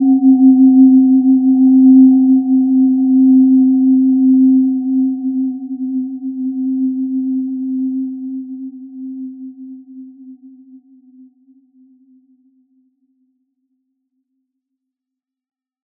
Gentle-Metallic-3-C4-p.wav